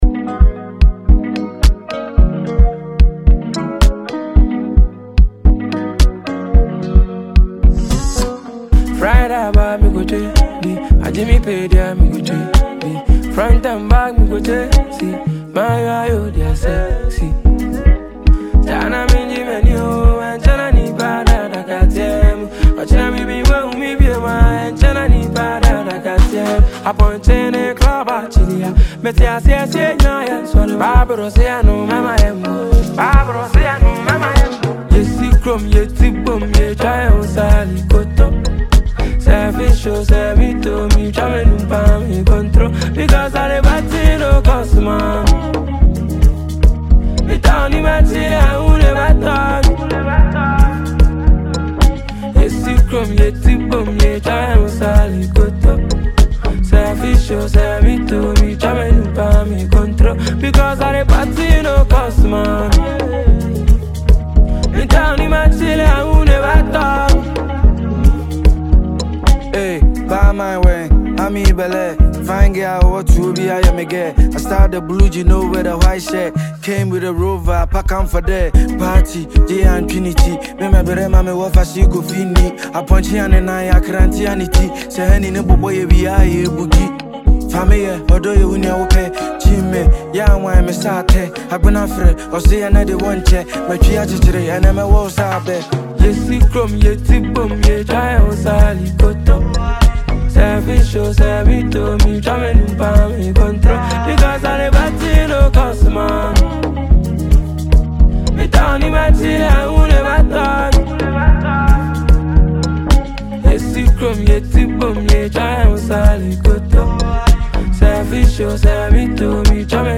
a Ghanaian singer